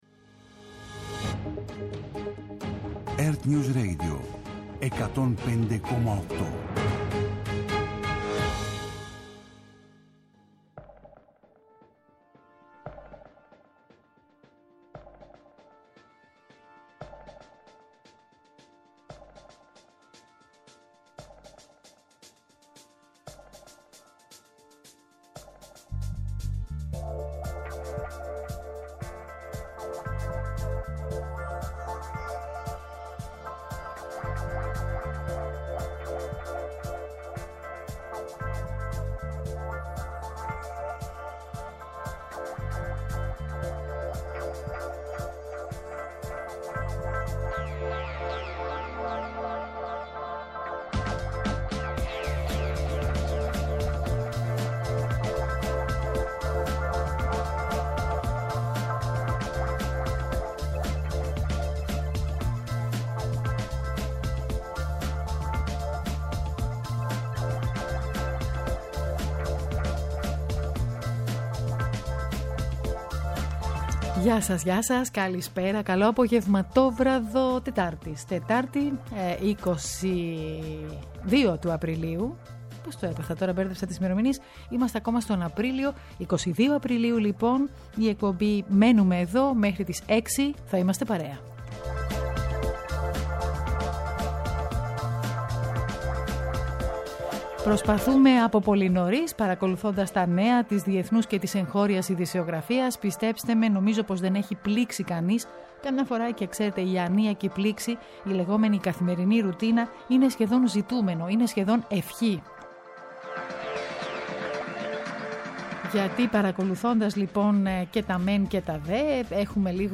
Σύνδεση με το 11ο Οικονομικό Φόρουμ των Δελφών
Ειδήσεις και απόψεις.